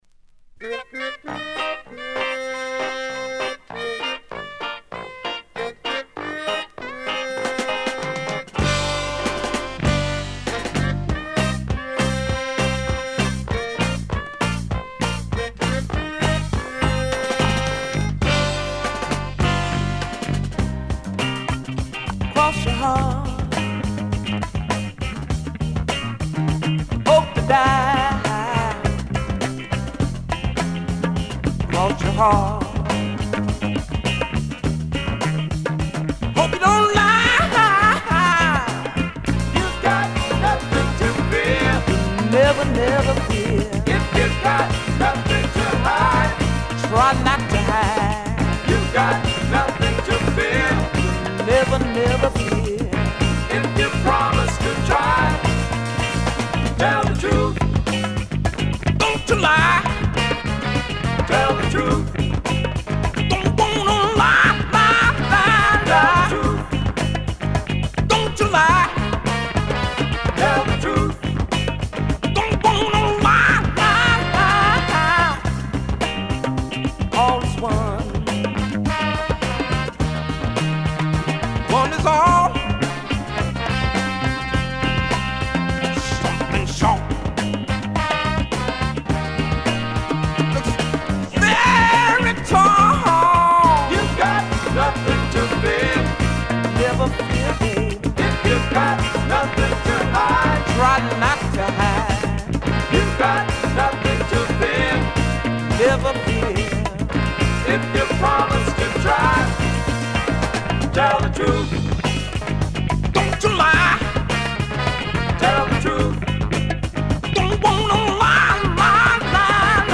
Funk/Soul
Side A→Side B(2:32～) 試聴はここをクリック ※実物の試聴音源を再生状態の目安にお役立てください。